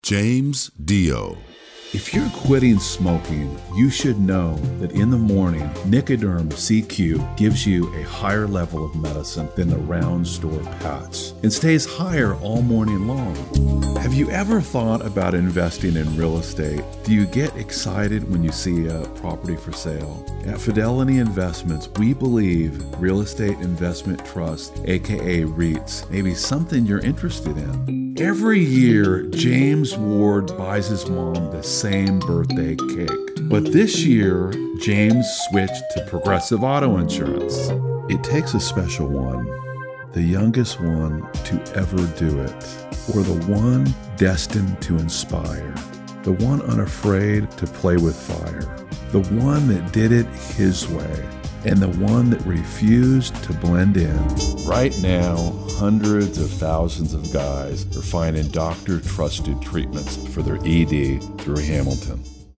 Demo Reel
talented male voiceover artist known for his warm, rich tone that captivates listeners